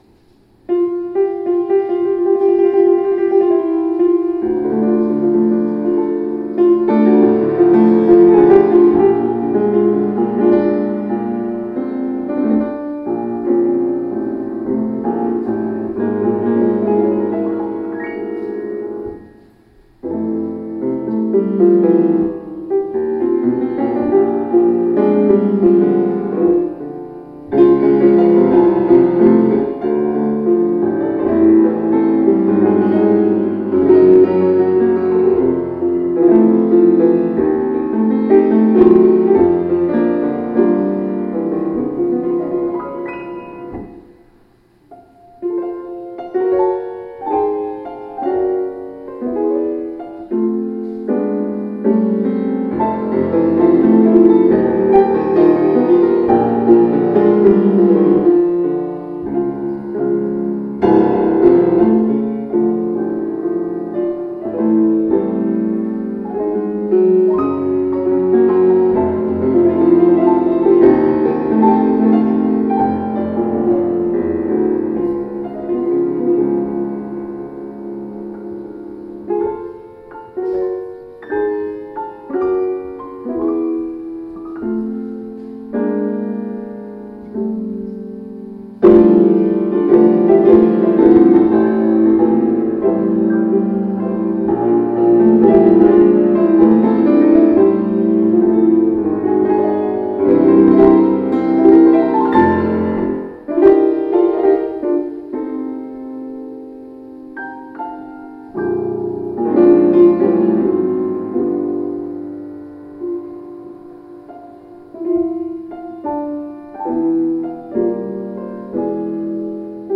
Subject: Deep River - Traditional African- American Spiritual